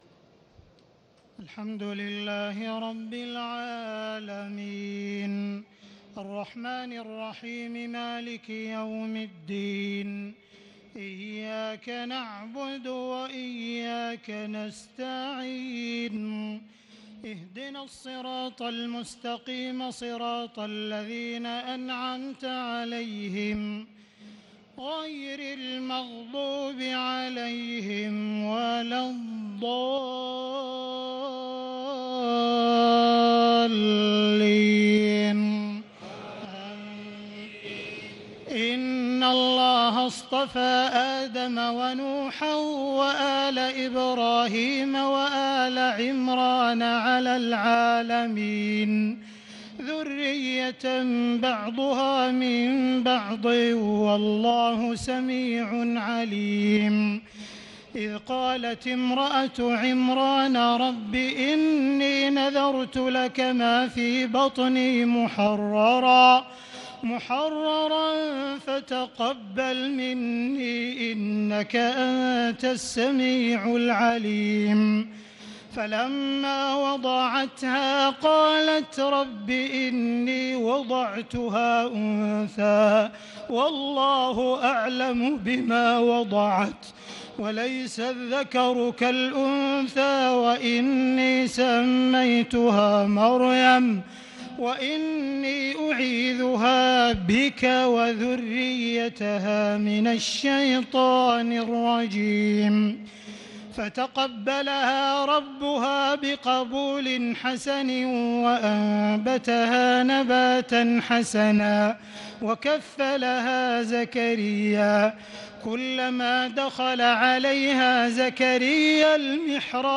تهجد ليلة 23 رمضان 1438هـ من سورة آل عمران (33-92) Tahajjud 23 st night Ramadan 1438H from Surah Aal-i-Imraan > تراويح الحرم المكي عام 1438 🕋 > التراويح - تلاوات الحرمين